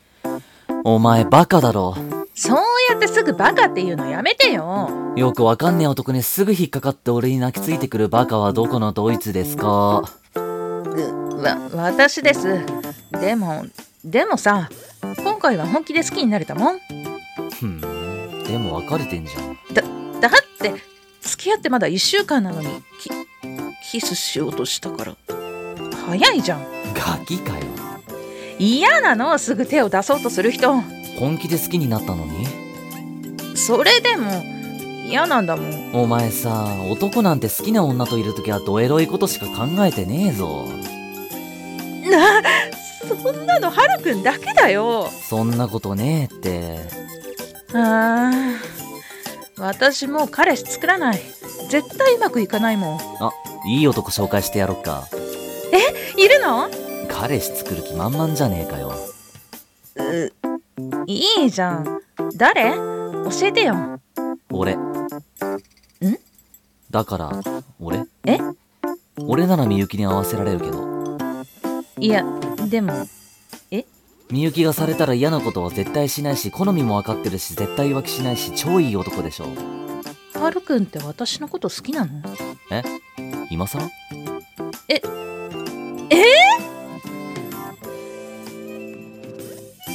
【二人声劇】突然ですが